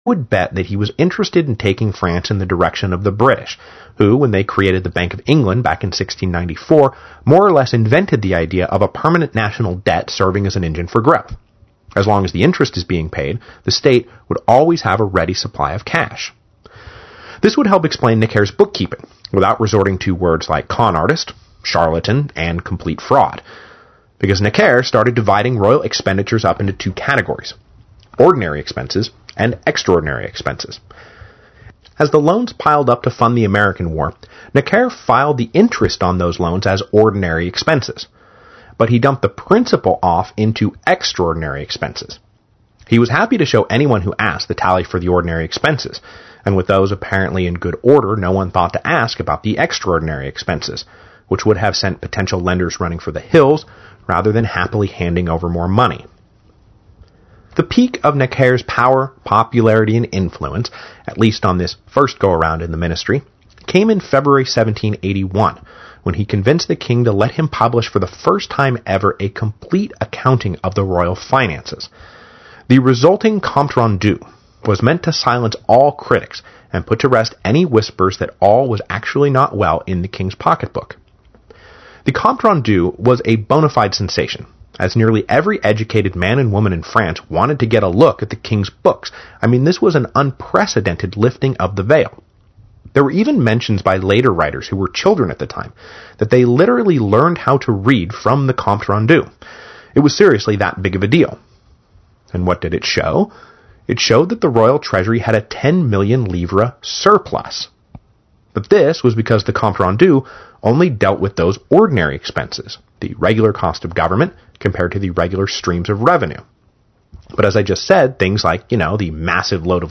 It is work done by American historian Mike Duncan and this is an excerpt from Episode 3.4- of Revolutions, available at his website or through iTunes. Duncan talks about Louis XVI official Jacques Necker and the Compte rendu.